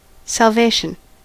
Ääntäminen
IPA : /sælˈveɪʃən/